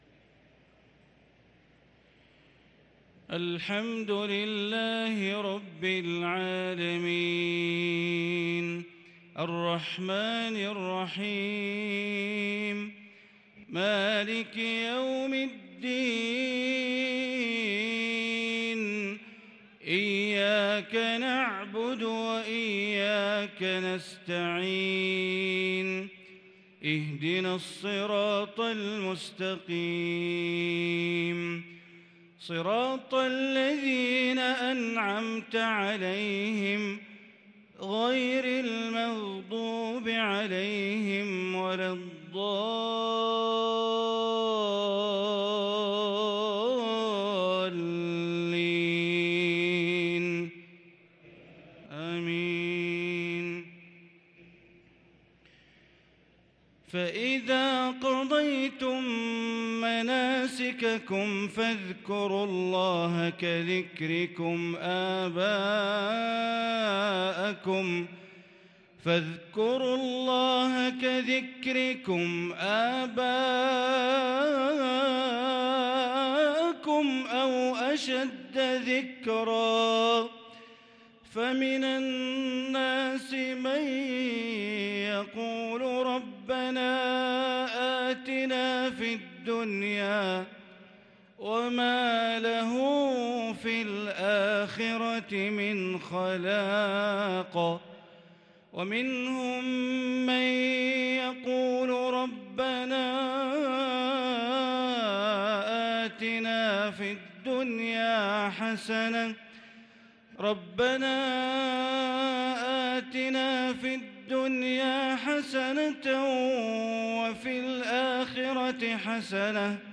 صلاة العشاء للقارئ بندر بليلة 12 ذو الحجة 1443 هـ